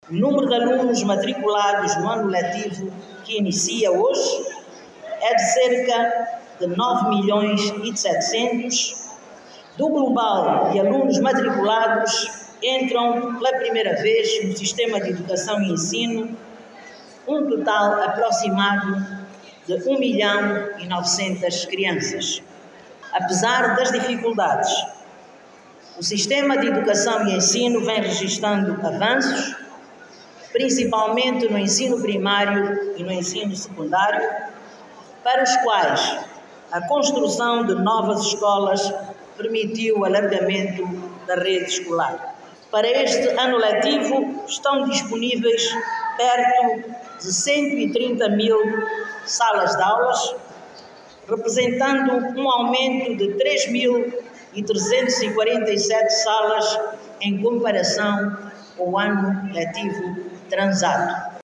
Os dados foram divulgados esta manhã  pela Ministra de Estado para área Social, Maria do Rosário Bragança, no acto oficial de abertura do ano lectivo que decorreu em Luanda. A governante anunciou, igualmente, que estão disponíveis perto de 130 Mil salas de aula que vão acolher a partir desta terça-feira alunos do ensino geral.